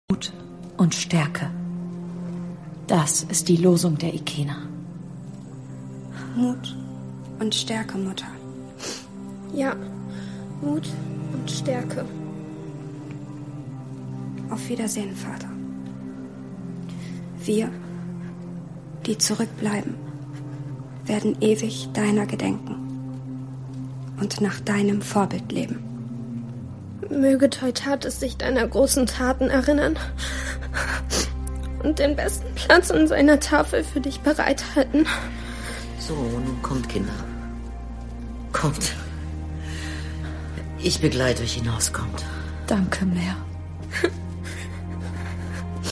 Sprecherin, Werbesprecherin